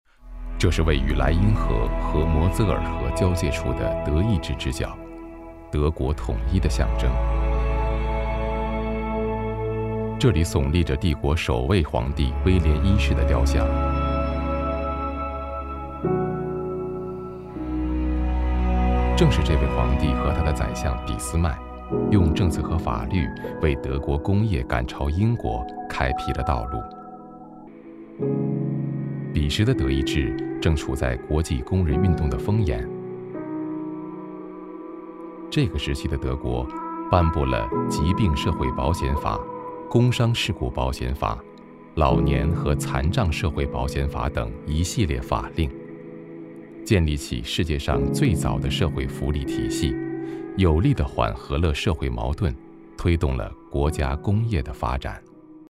娓娓道来 文化历史
大气、高性价比男中音，擅长专题解说配音、宣传片配音、科技感配音、颁奖配音等题材。